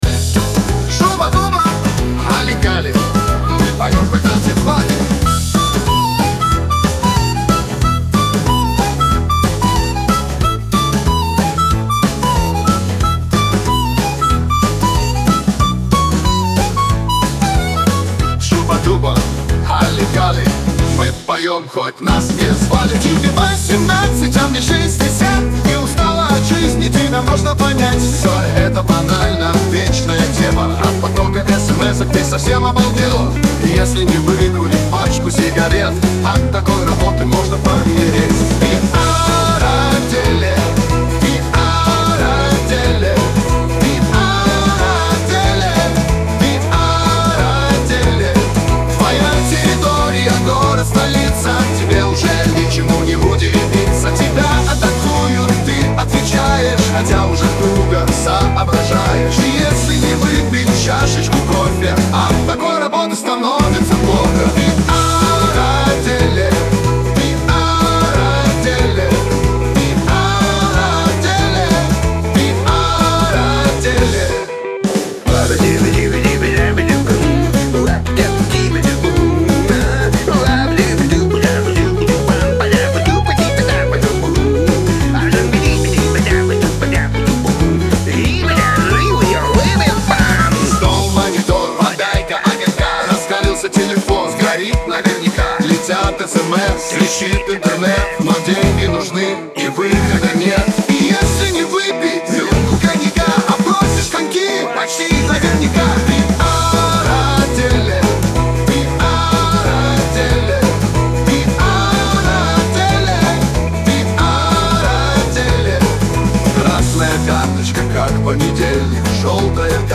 неспетые песни времен СССР